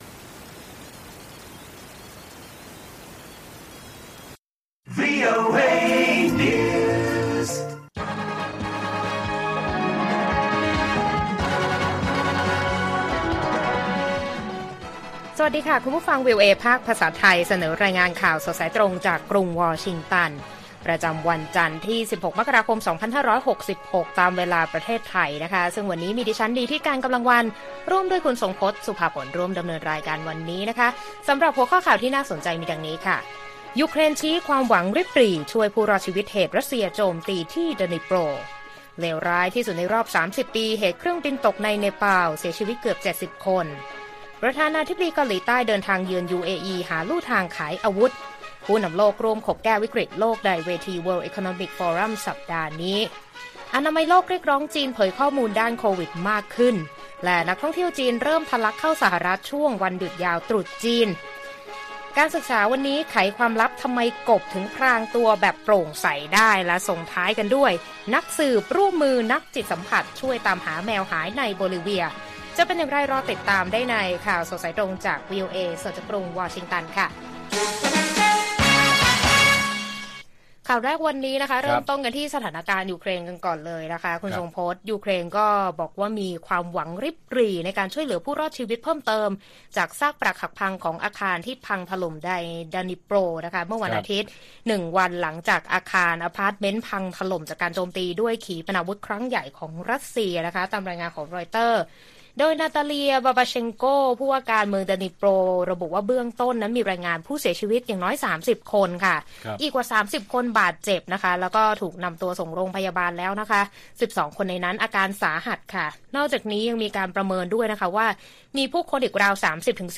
ข่าวสดสายตรงจากวีโอเอไทย 6:30 – 7:00 น. วันที่ 16 ม.ค. 66